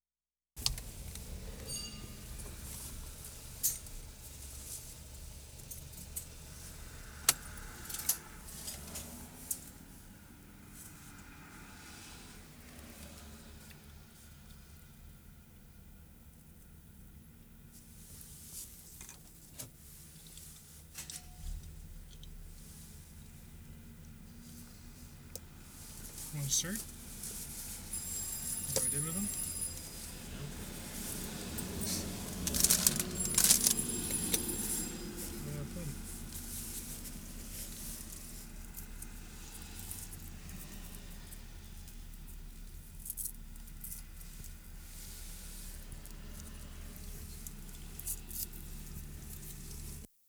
WORLD SOUNDSCAPE PROJECT TAPE LIBRARY
FRASER RIVER SHORELINE TOUR March 21, 1973
GAS STATION 0'50"
8. Stopping the car, fast moving traffic passes, some bell rings somewhere.